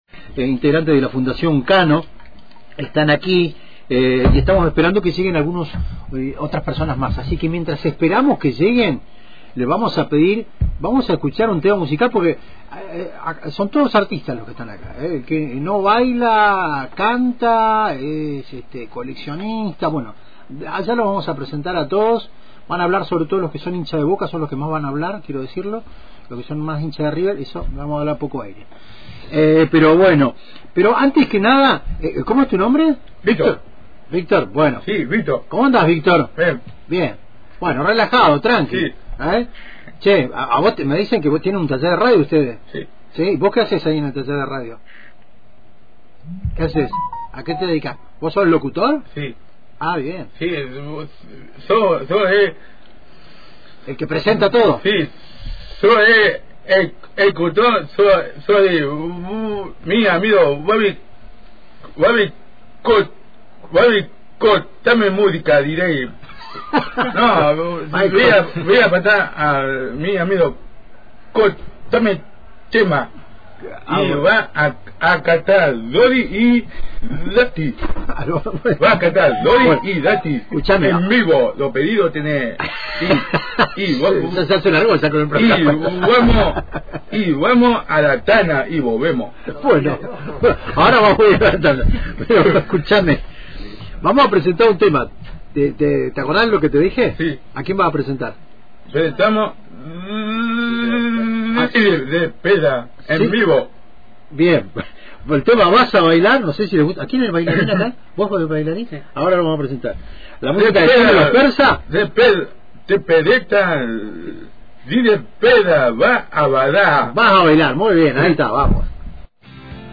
En el estudio «Madres de Plaza de Mayo» de Antena Libre, integrantes de la Fundación Kano compartieron sus experiencias, talentos y proyectos, demostrando cómo el arte, la comunicación y la creatividad abren caminos para las personas con discapacidad.
También se abordaron las dificultades que enfrenta este colectivo frente a los recortes y la falta de cobertura de las obras sociales. Con emoción, música y humor, los chicos cerraron el programa enviando saludos y celebrando el espacio compartido.